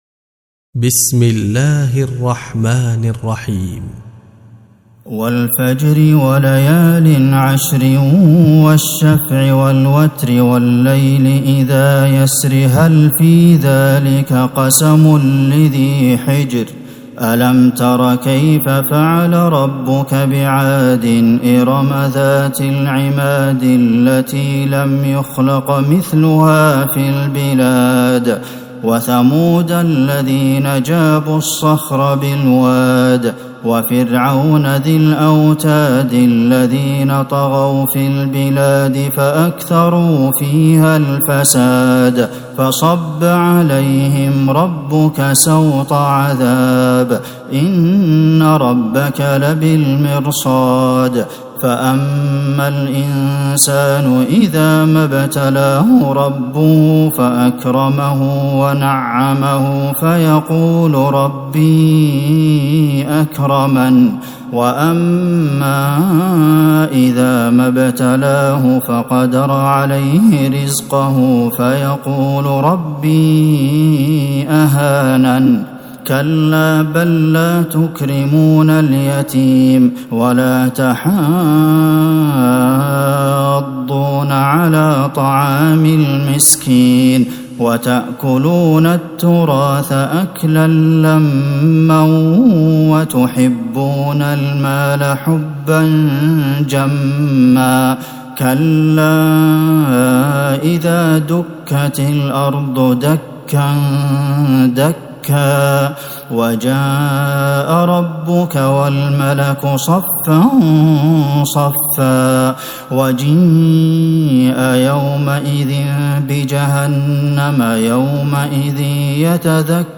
سورة الفجر Surat Al-Fajr من تراويح المسجد النبوي 1442هـ > مصحف تراويح الحرم النبوي عام 1442هـ > المصحف - تلاوات الحرمين